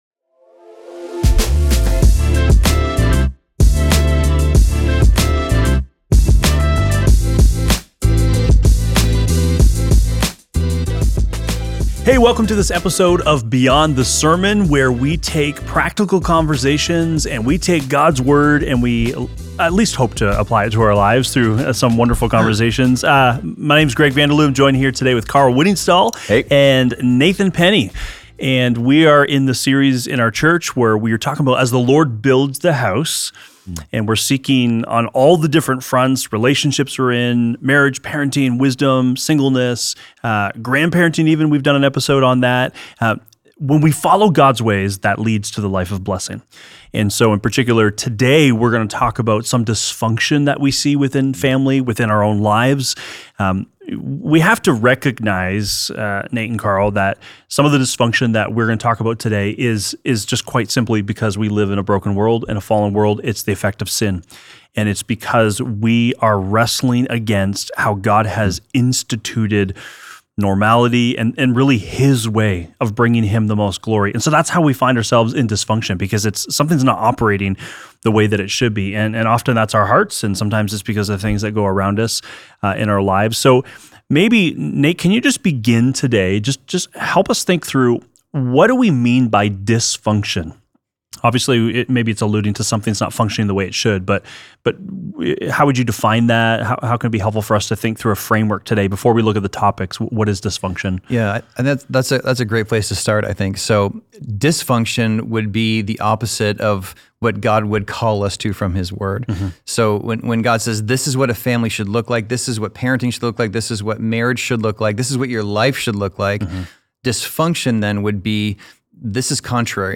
Conversations on Dysfunction in the Family